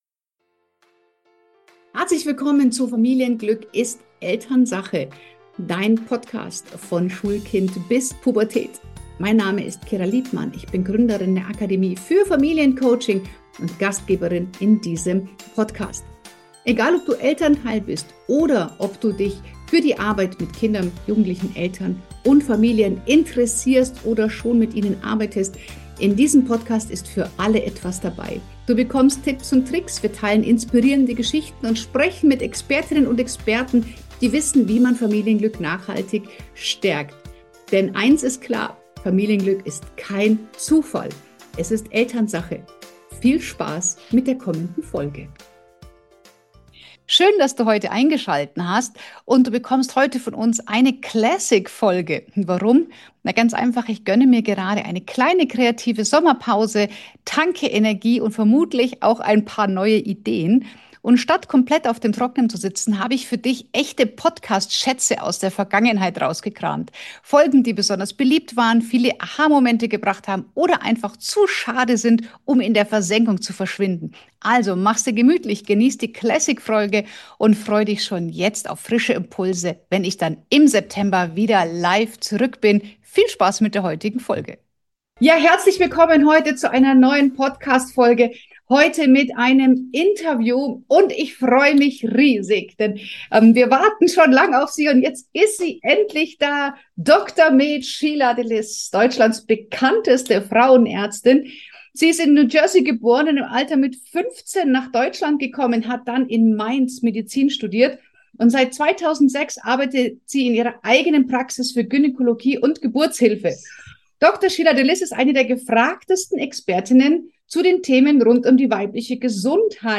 #313 Girl on Fire - Sexualität in der Pubertät - Interview